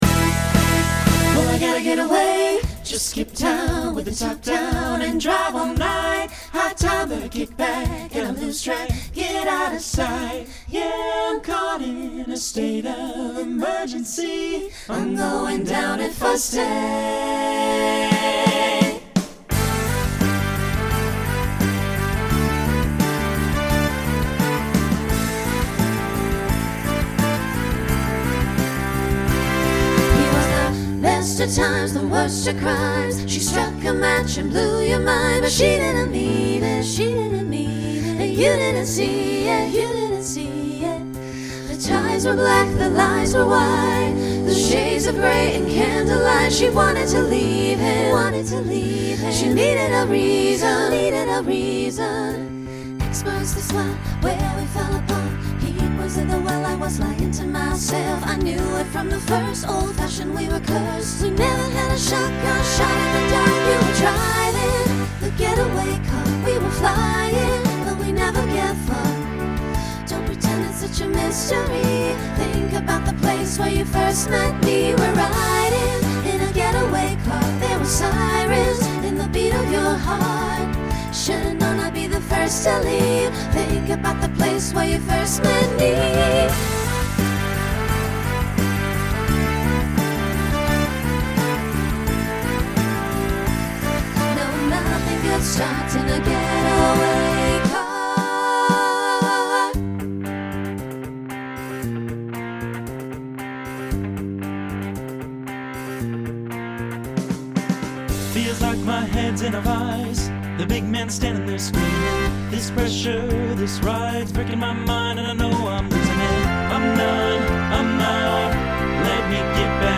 Genre Country , Pop/Dance , Rock
Transition Voicing Mixed